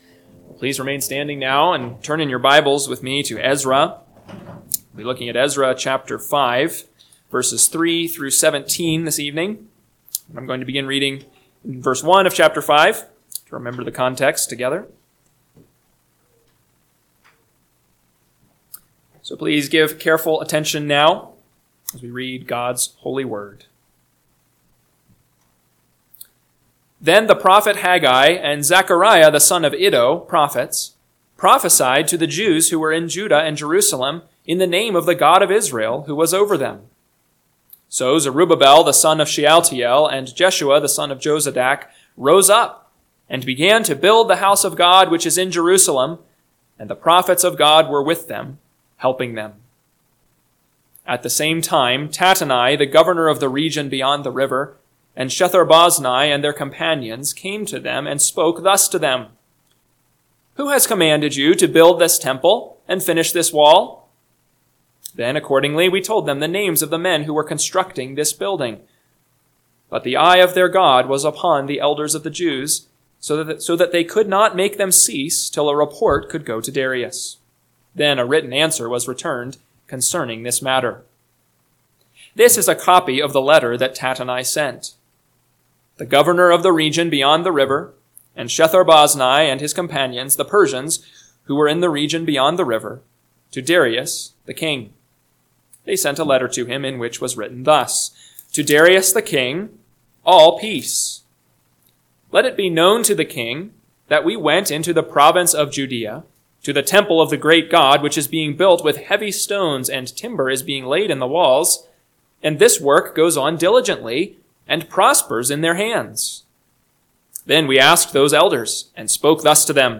PM Sermon – 4/6/2025 – Ezra 5:3-17 – Northwoods Sermons